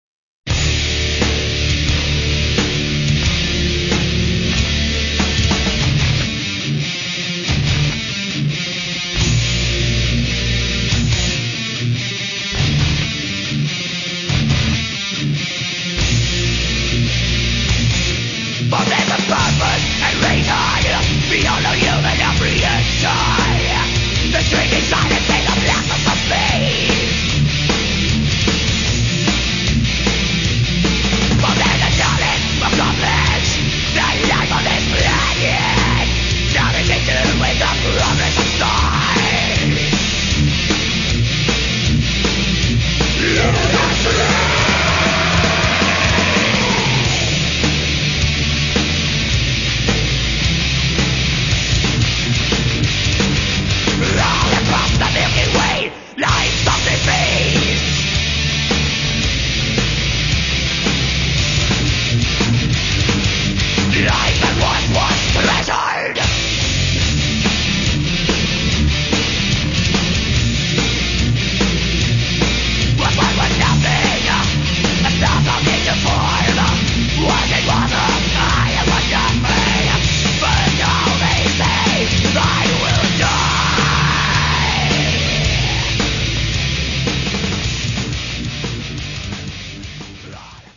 Все файлы размещены с качеством 32 Кбит/с, 22 кГц, моно